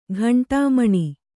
♪ ghaṇṭā maṇi